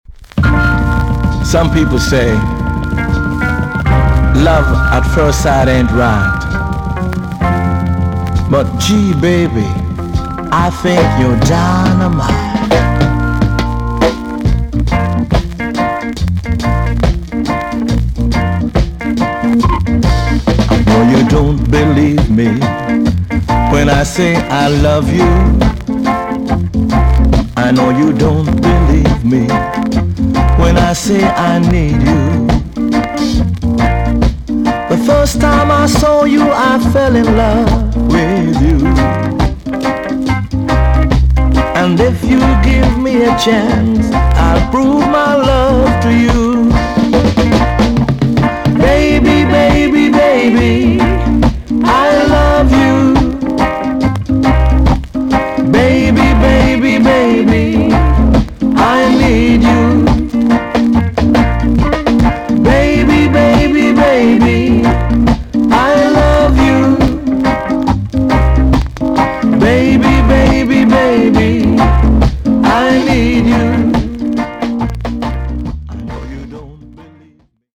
TOP >REGGAE & ROOTS
VG+ 軽いチリノイズがあります。
NICE UK LOVERS TUNE!!